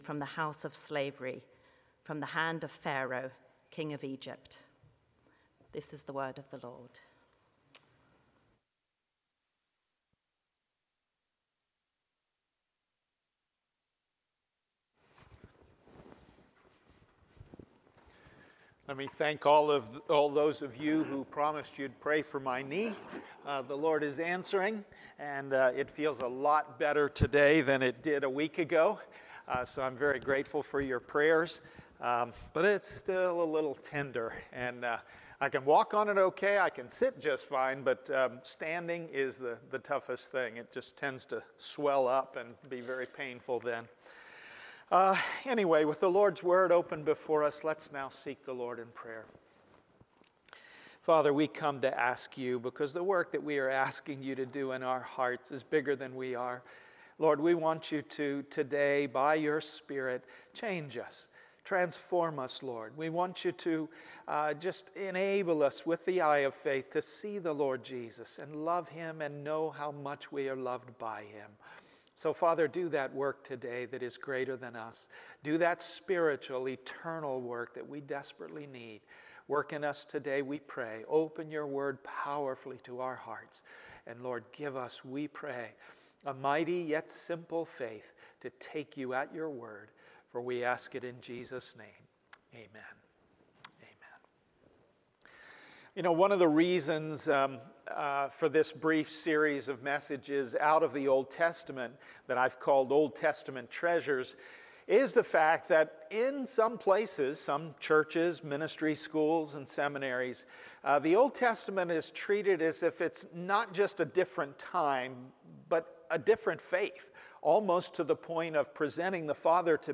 Passage: Deuteronomy 7:6-8 Sermon